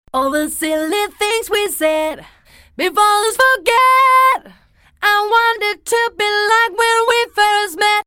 il faut connaître la tonalité de base du morceau (ou tour de chant) qu'on programme dans l'Antares (on y rentre les notes de la tonalité) et la vitesse de correction est réglé au minimum ce qui donne un effet déjà bien audible (les petites notes intermédiaires sont ramené aux notes programmées les plus proches
108vox-tunemaj.mp3